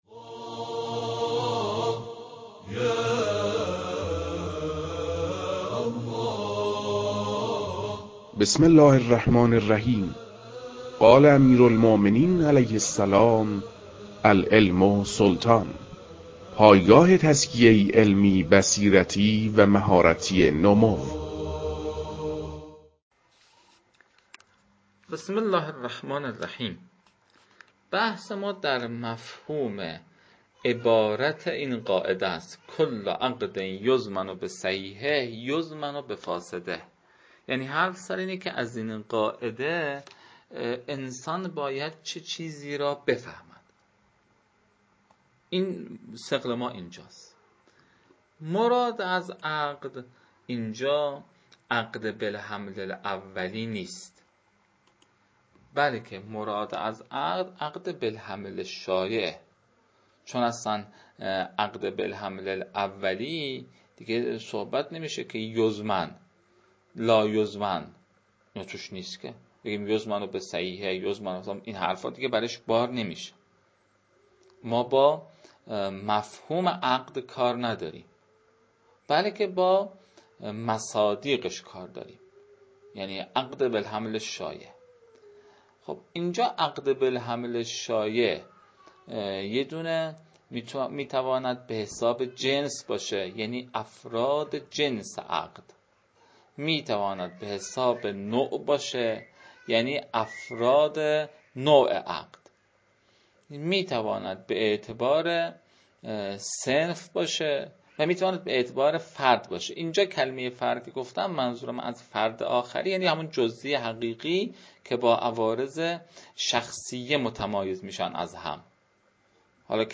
توصیه می‌شود جهت استفاده بهتر از فایل‌های این‌چنینی خودتان را مانند یکی از اعضای کارگاه قرار داده و در پرسش و پاسخ‌ها ذهنتان را همراه حضار جلسه درگیر مباحث نمایید.